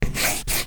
铅笔书写10(Pencil Writing 10)_爱给网_aigei_com.wav